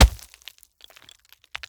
RockHitingGround_2.wav